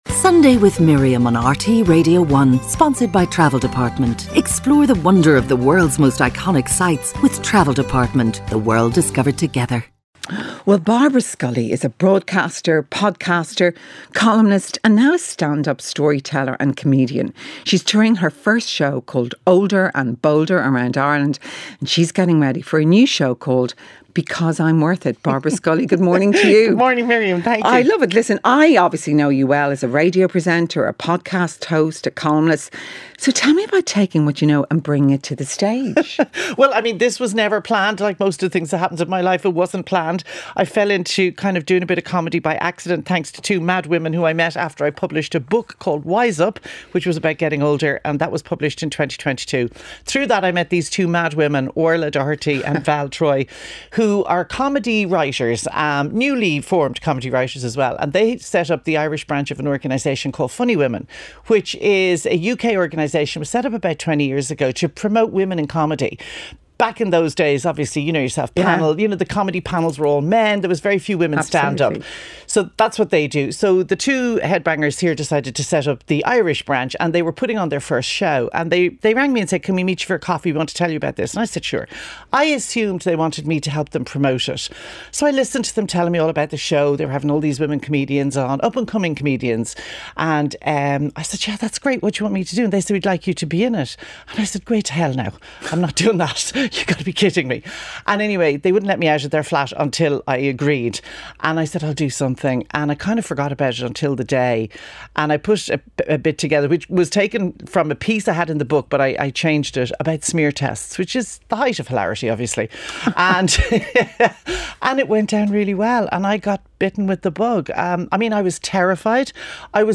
Miriam O'Callaghan presents an all-talking, all-singing, all-human-life-is-here show.